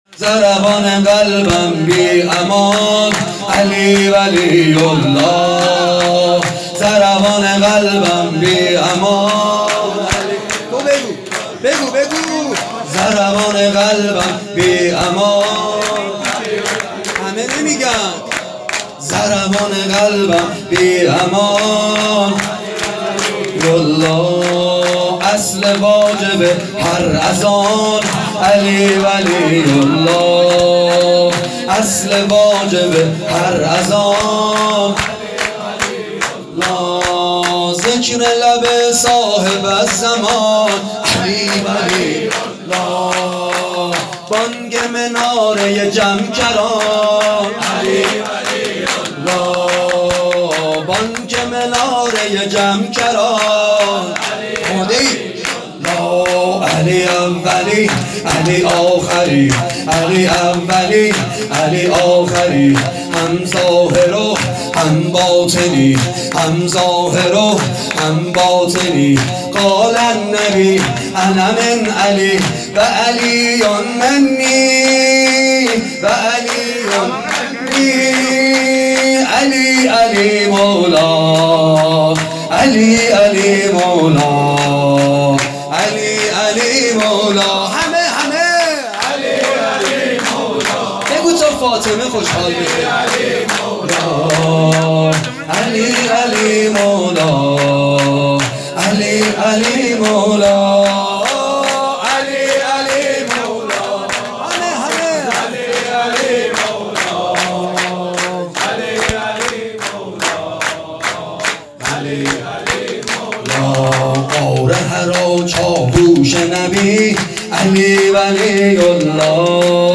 شور امیرالمومنین علي(ع) _ضربان قلبم بی امان،علی ولی الله
میلاد حضرت زهرا سلام الله۹۷